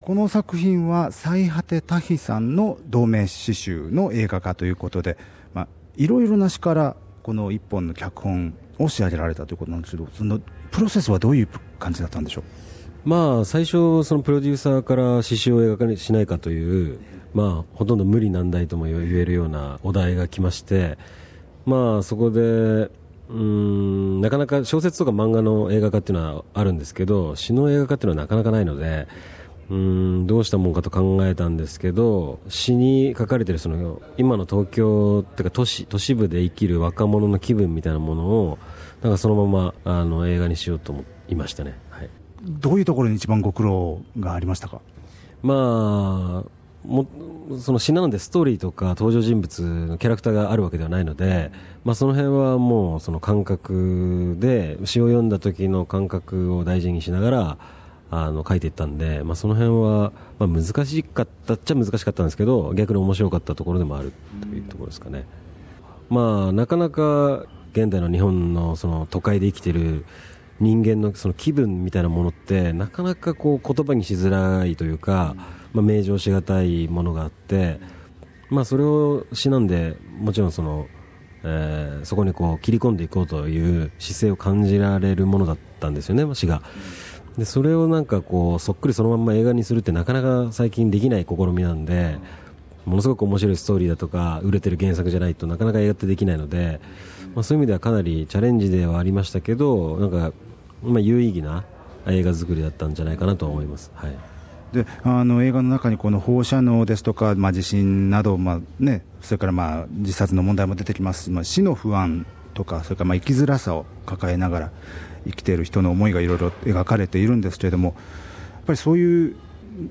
interviews Ishii who visited Sydney to present the film at the Japanese Film Festival.